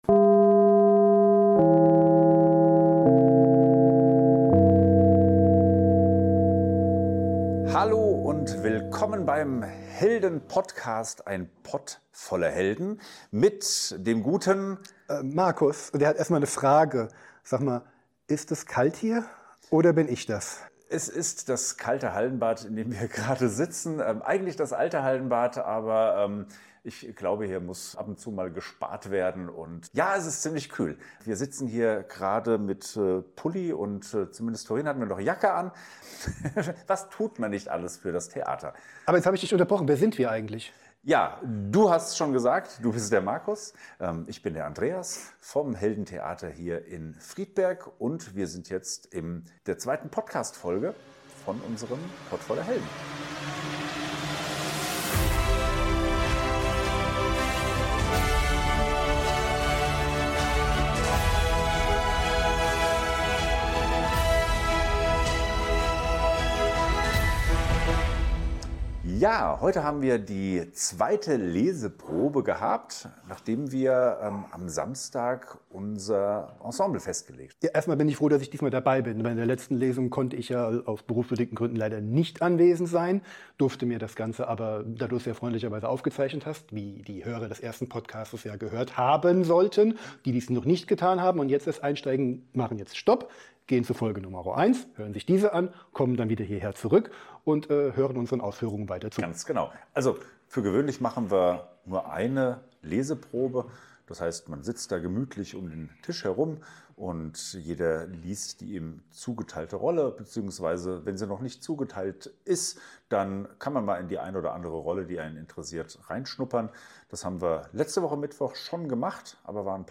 Von Tücke der Übersetzung, der 2. Lesung und getroffenen Rollenentscheidung! Live aus dem unbeheizten Keller, dafür frisch auf den Tisch.